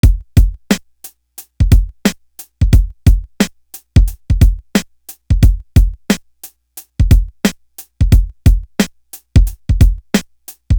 Soul Drum.wav